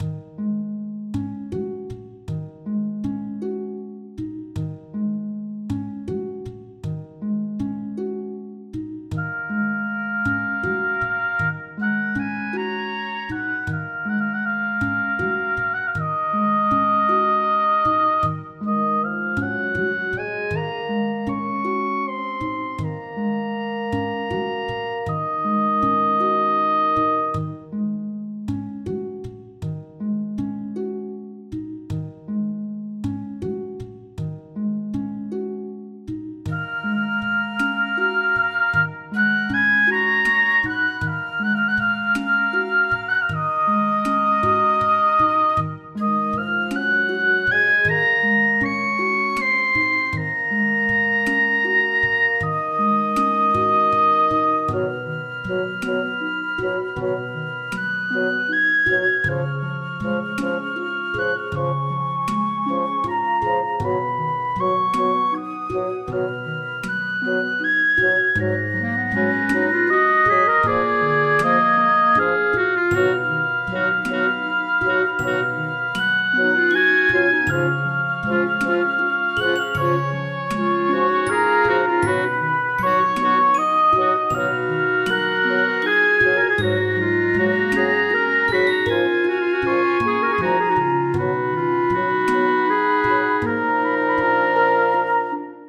フィールド